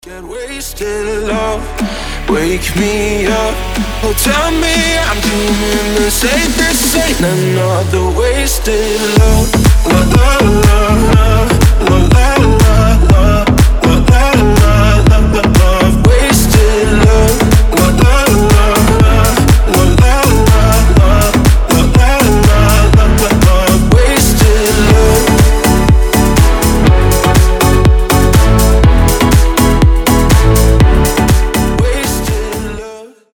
• Качество: 320, Stereo
мужской голос
house
slap house